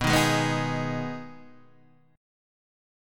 Abm/Cb Chord